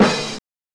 SNARE 90S 3.wav